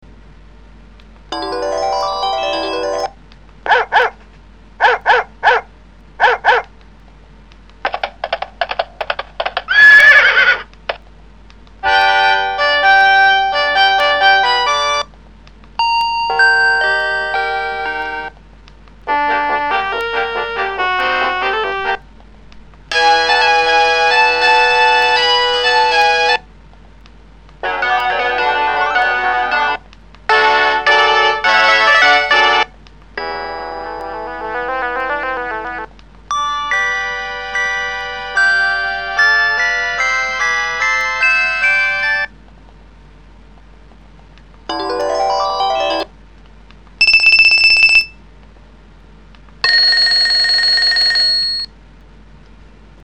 It supports Mid & Midi for poly ring tones and it can play 16 notes at once although it sounds more like 40 tones at once. It is also very loud much louder then the Nokia 7650 and very clear it is much like music to my ears.